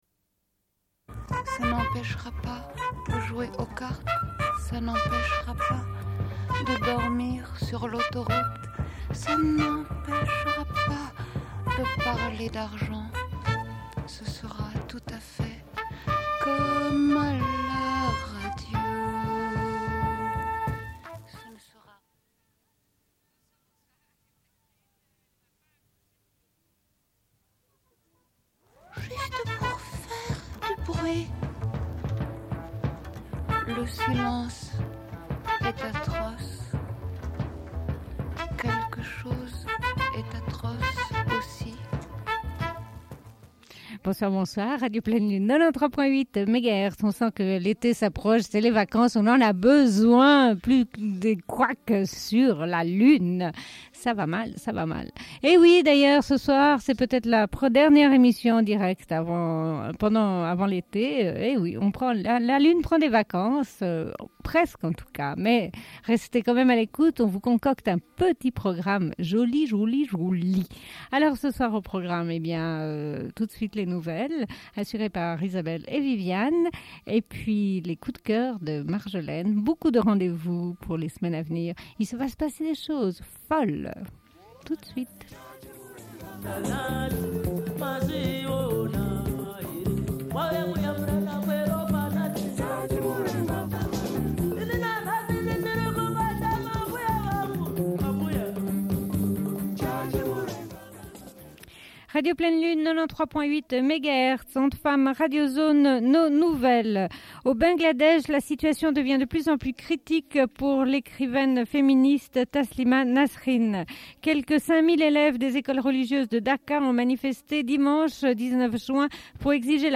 Bulletin d'information de Radio Pleine Lune du 16.02.1994 - Archives contestataires
Une cassette audio, face B29:26